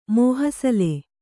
♪ mōhasale